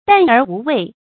淡而无味 dàn ér wú wèi
淡而无味发音